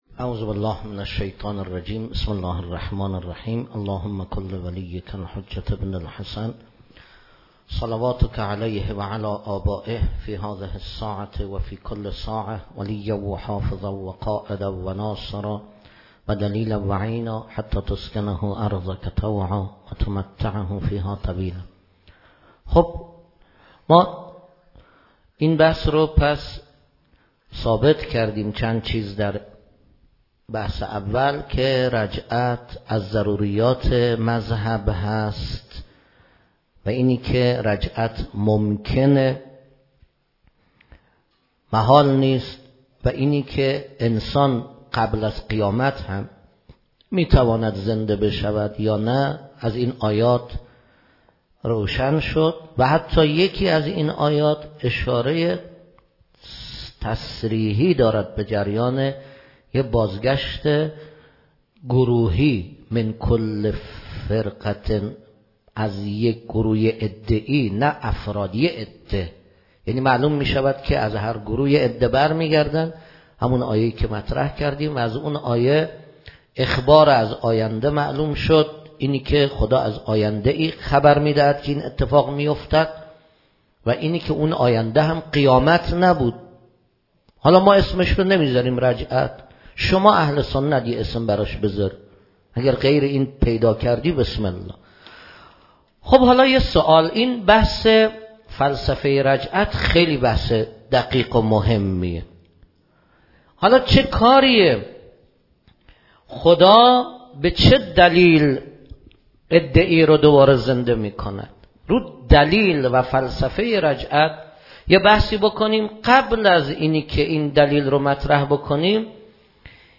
صوت سخنرانی